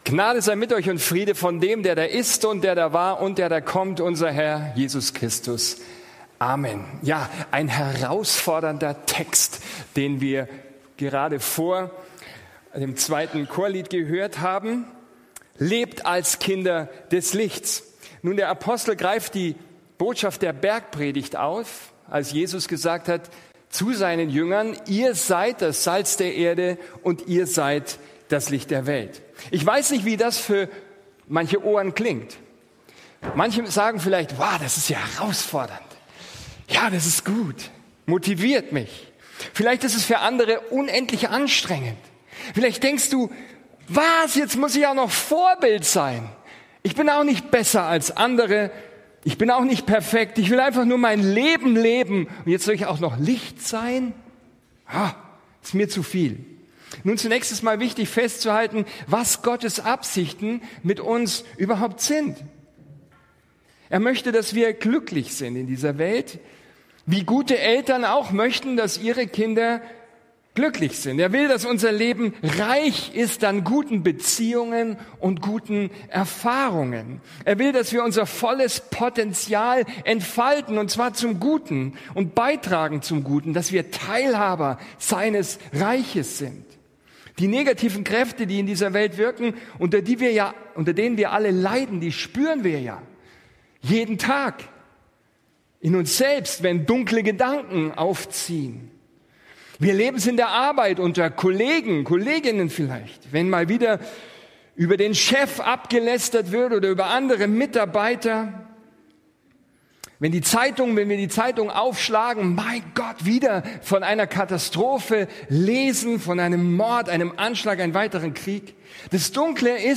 Ein Studienblatt zur Predigt ist im Ordner “Notizen” (Dateien zum Herunterladen) verfügbar